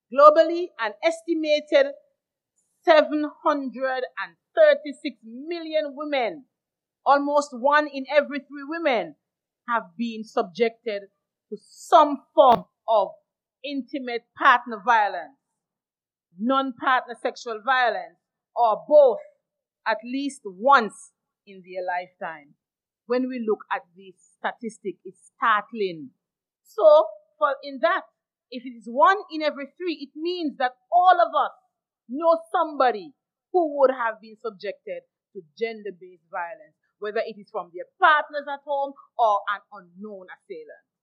The launch ceremony was held at the Artisan Village, at Pinney’s, Nevis.
Here is an excerpt from Minister Brandy-Williams’ address on that day: